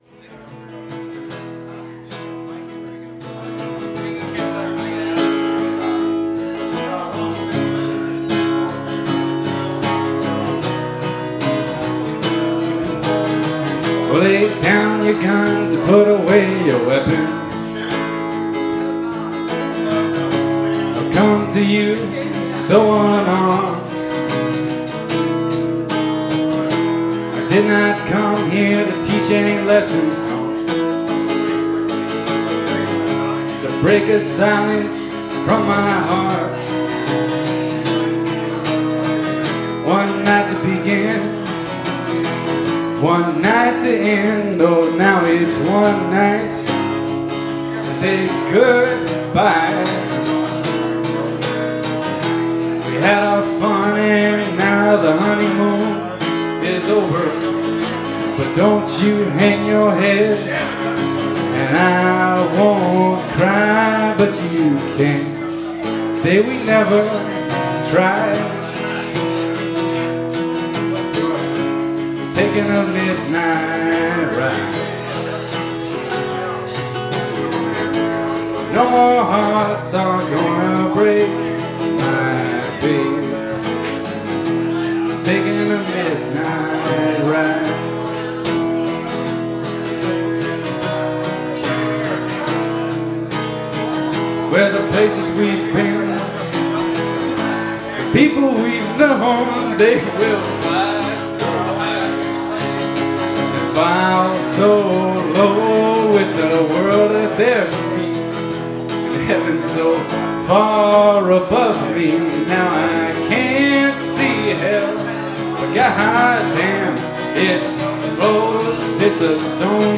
from New Year’s at the Cabin Tavern